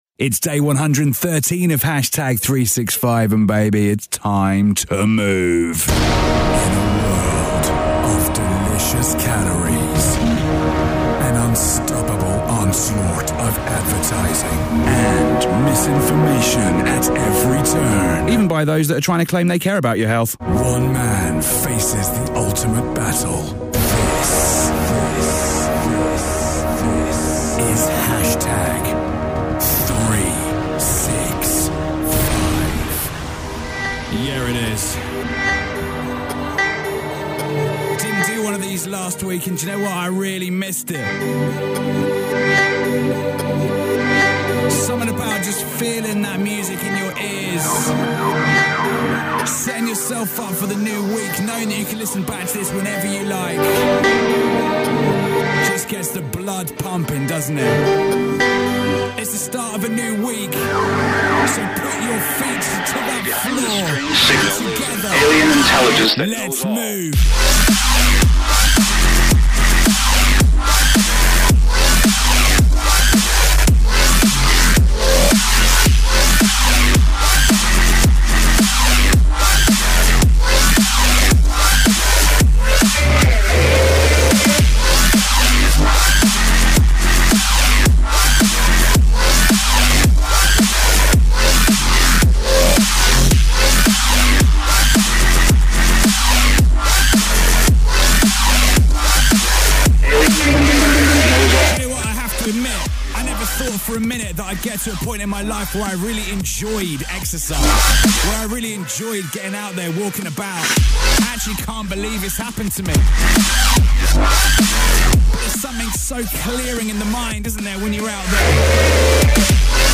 (NB: Music is a bit distorted, but I actually thought it sounded kinda gnarly, so just left it...ENJOY!)